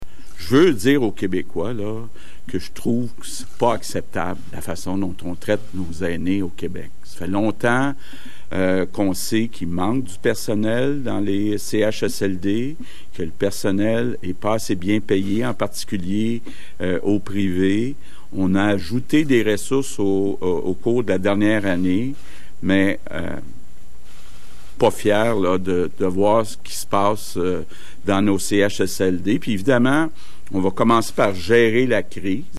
Le premier ministre François Legault, qui devait prendre congé samedi, s’est finalement adressé à la presse et aux Québécois en début d’après-midi.
François Legault, visiblement troublé par cette affaire, a promis qu’une fois la crise passée, son gouvernement reverra  toutes les façons de faire dans les résidences pour aînés.